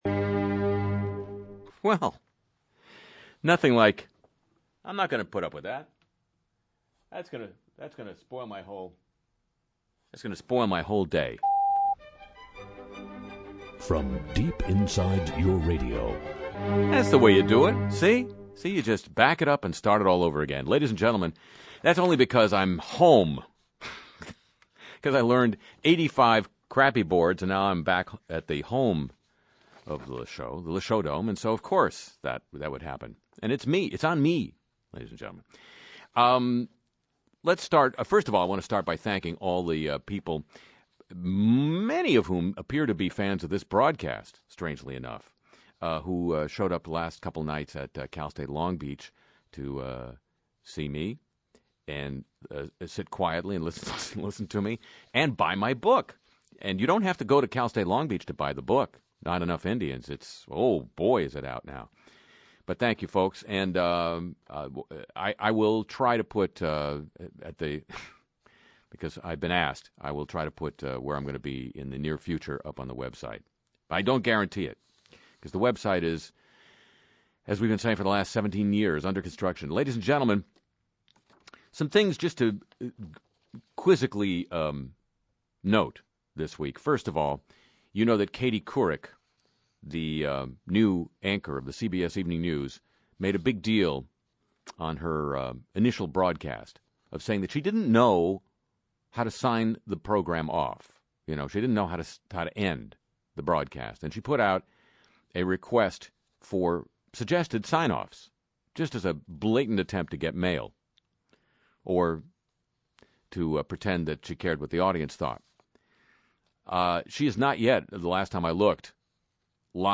Music & Segments